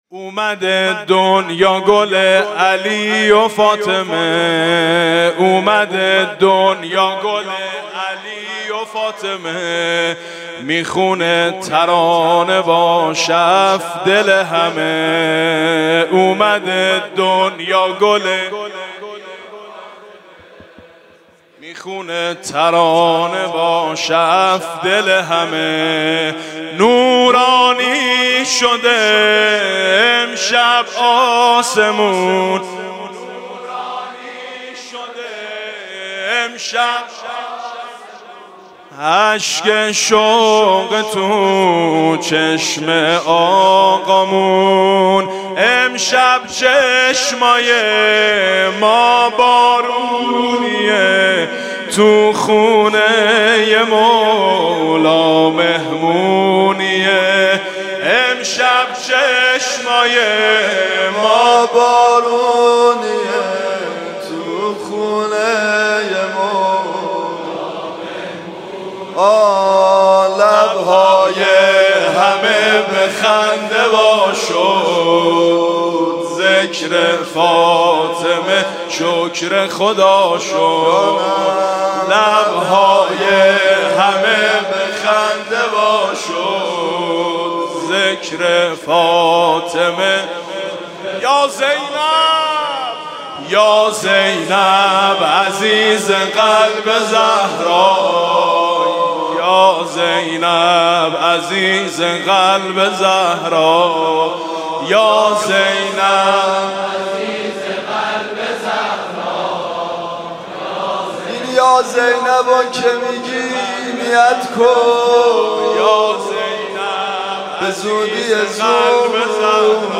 ولادت حضرت زینب (سرود)